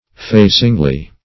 facingly - definition of facingly - synonyms, pronunciation, spelling from Free Dictionary Search Result for " facingly" : The Collaborative International Dictionary of English v.0.48: Facingly \Fa"cing*ly\, adv. In a facing manner or position.